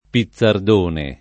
[ pi ZZ ard 1 ne ]